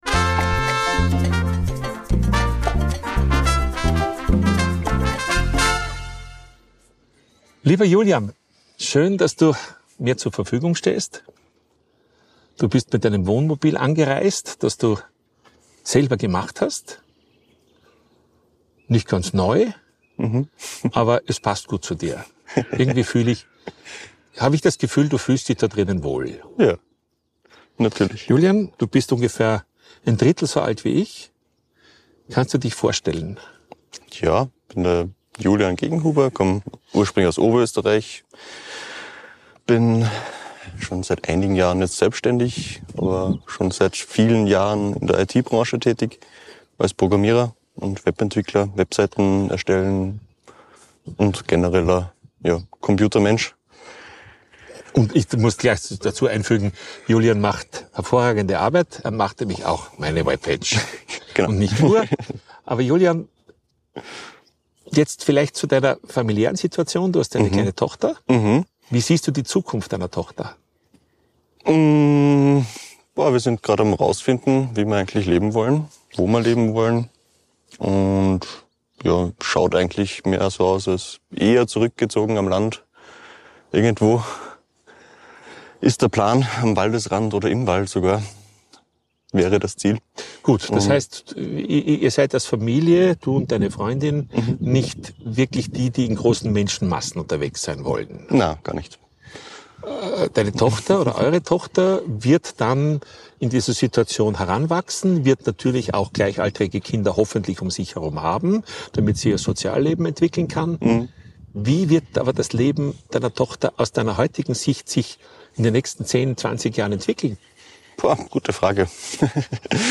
Ein Gespräch über Unabhängigkeit, Partnerschaft auf Augenhöhe – und das gute Gefühl, keine Angst vor der Zukunft zu haben.